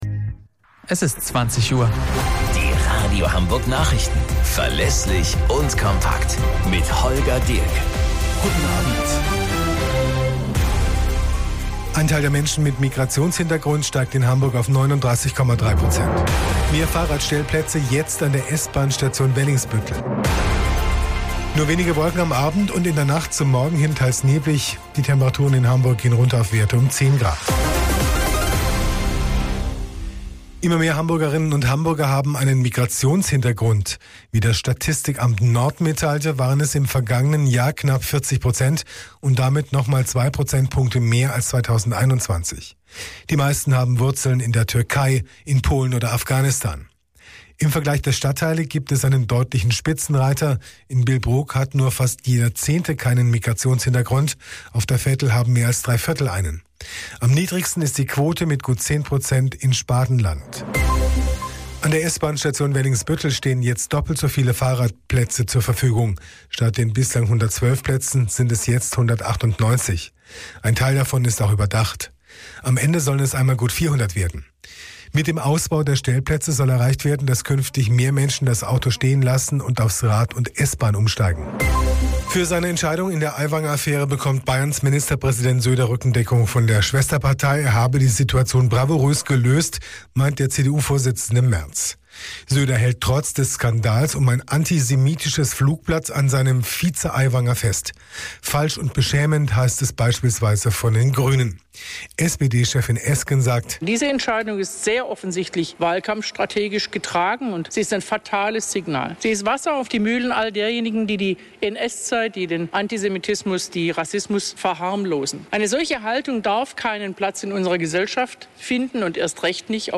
Radio Hamburg Nachrichten vom 04.09.2023 um 20 Uhr - 04.09.2023